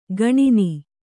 ♪ gaṇini